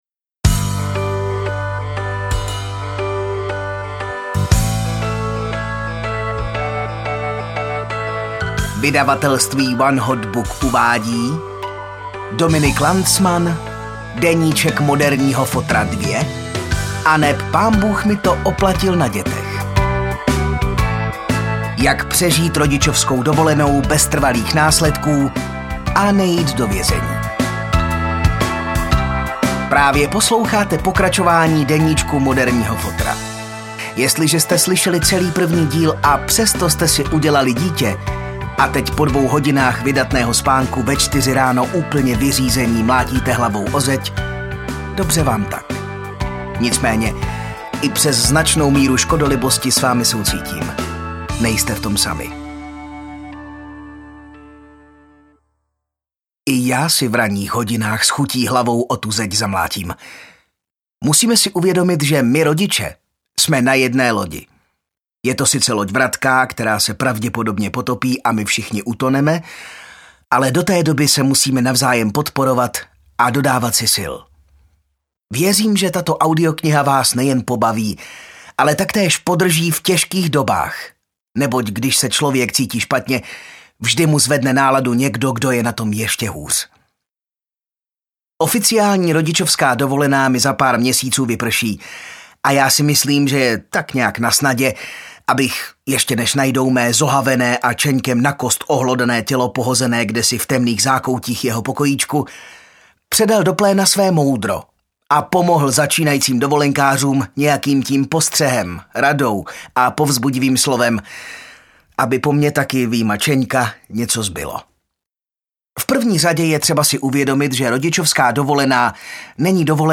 AudioKniha ke stažení, 74 x mp3, délka 11 hod. 36 min., velikost 620,0 MB, česky